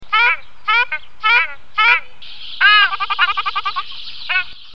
casarca.mp3